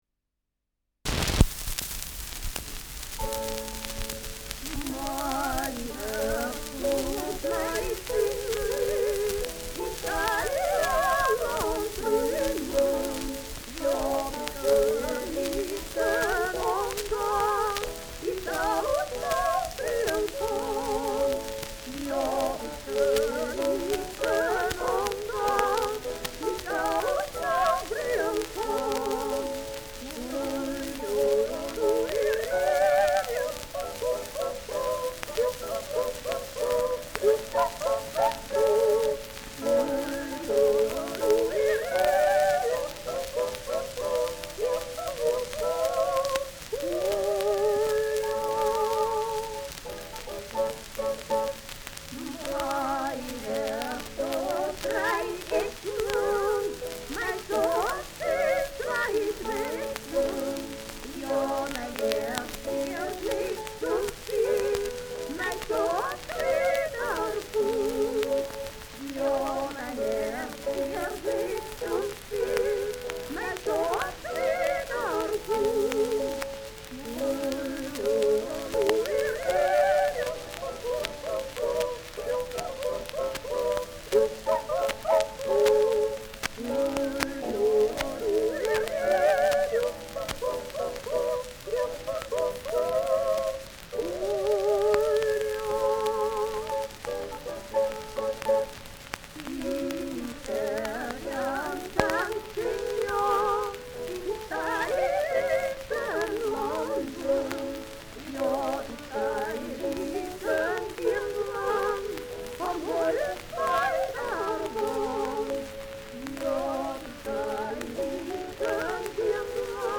Herz und Sinn : Steirisches Lied
Schellackplatte
Stärkeres Grundrauschen : Durchgehend leichtes Knacken : Leiern